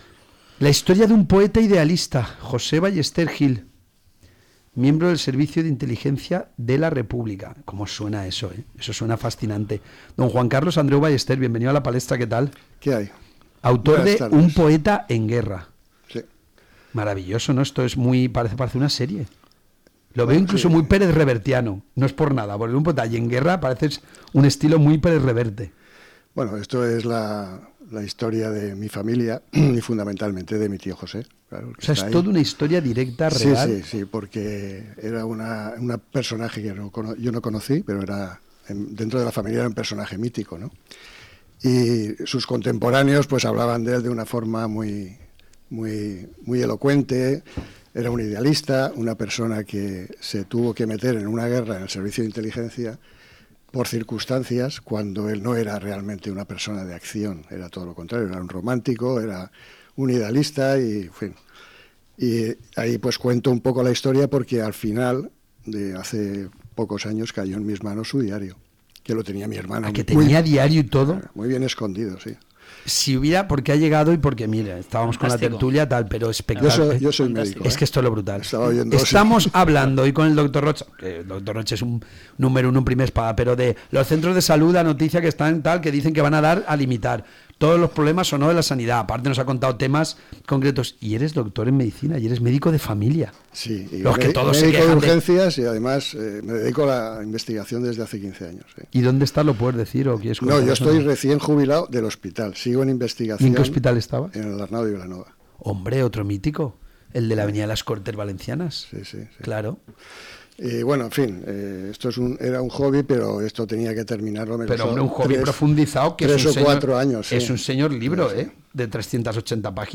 Entrevista en Plaza Valencia
Entrevista-Un-poeta-en-guerra1.m4a